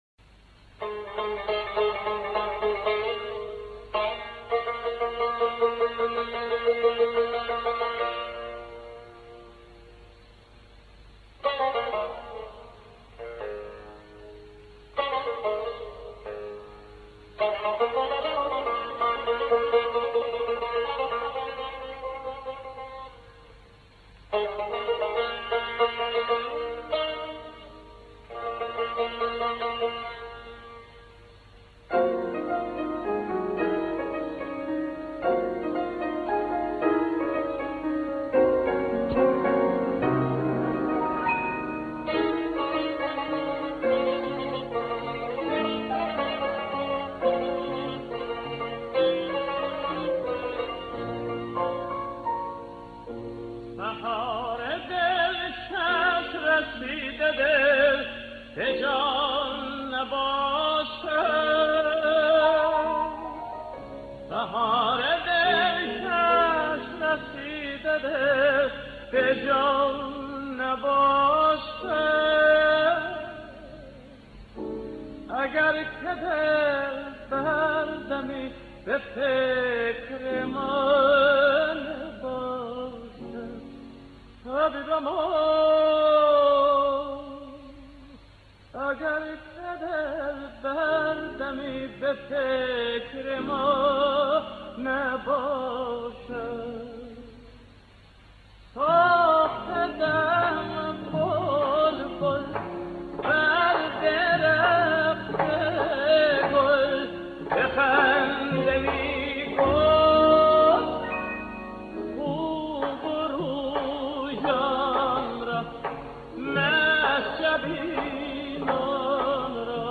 лирический тенор